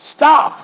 synthetic-wakewords
ovos-tts-plugin-deepponies_Kanye West_en.wav